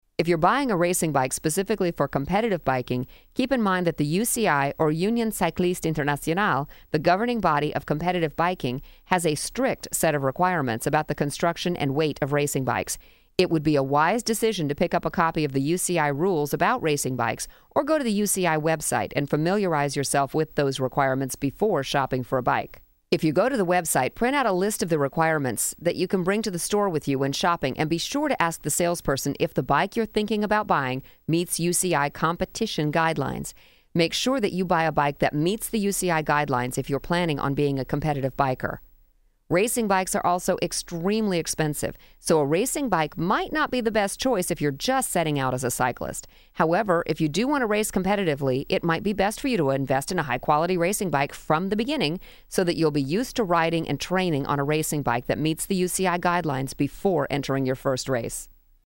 Biking - How To Ride Strong Audio Book
Biking-How-To-Ride-Strong-Audio-Sample.mp3